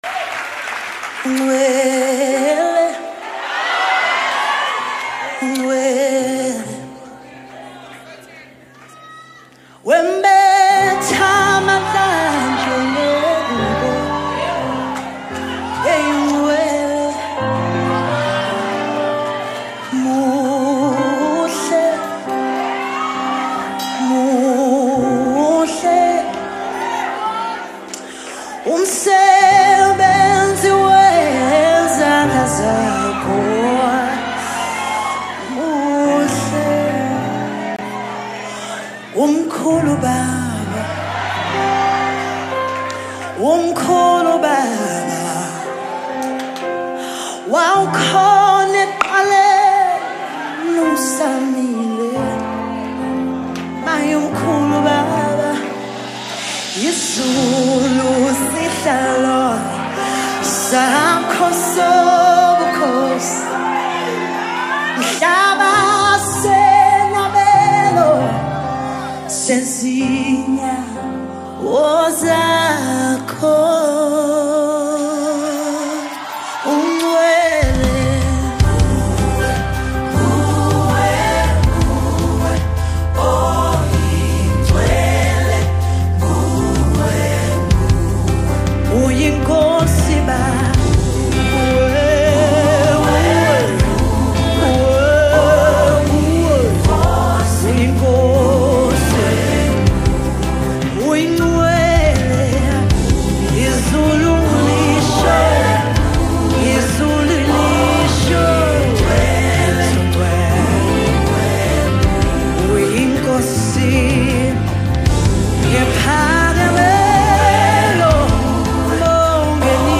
Live MusicWorship Songs
A powerful worship song filled with God’s presence
Soulful and anointed vocals
📅 Category: South African Worship Song